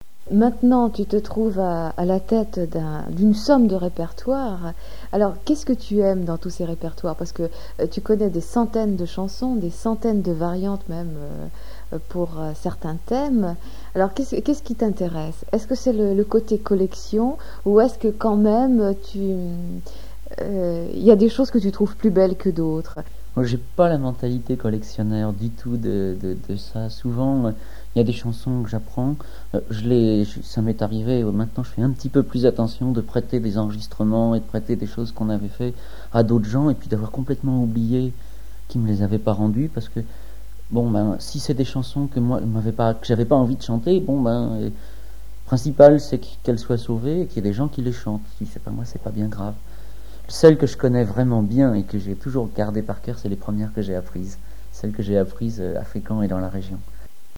Interview
Témoignage